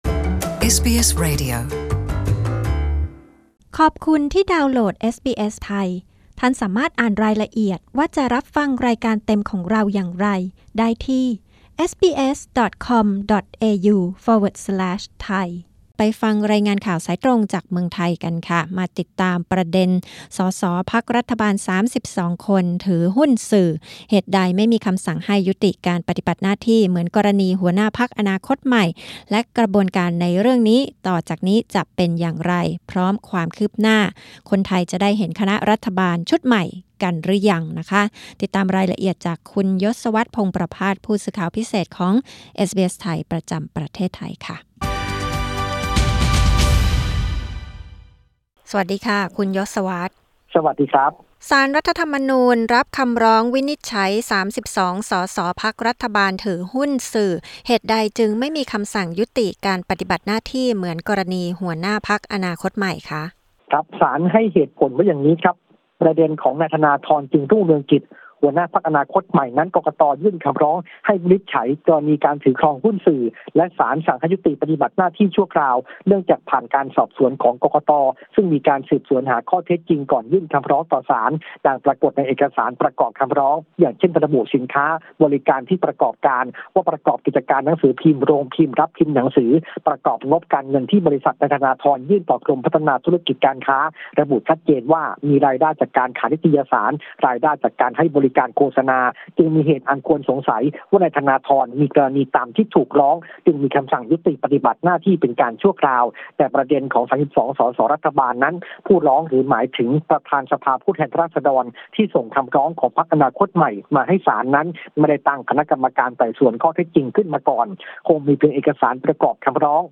กดปุ่ม (▶) ด้านบนเพื่อฟังรายงานข่าว รายงานนี้นำเสนอในรายการวิทยุเอสบีเอส ไทย เมื่อคืนวันพฤหัสบดี ที่ 27 มิ.ย.